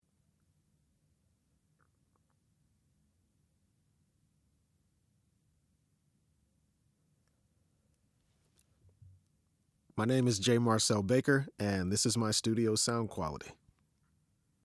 Commercial
Male
20s, 30s
Confident, Conversational, Cool, Energetic, Engaging, Natural, Smooth, Streetwise, Upbeat, Warm
Microphone: Neumann TLM103, Sennheiser 416
Audio equipment: UAD Apollo Twin X (Avalon & Manley Preamps)